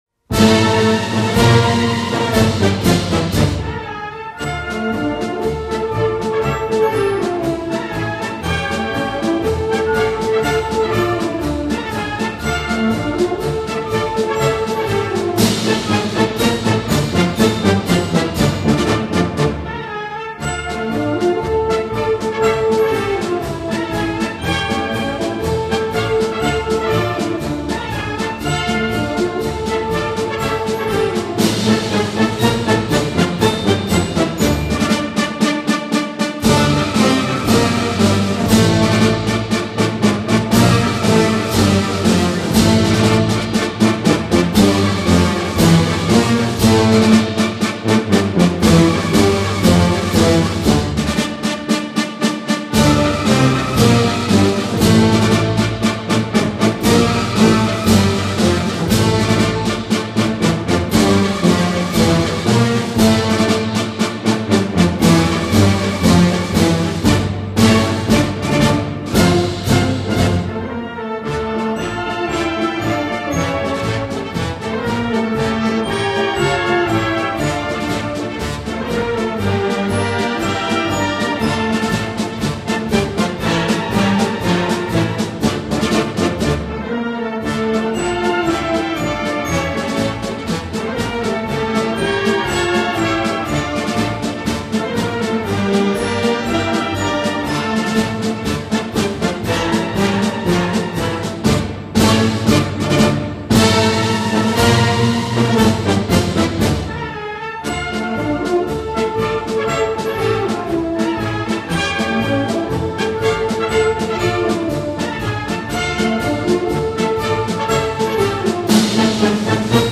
"Тоска по родине" марш (закрыта)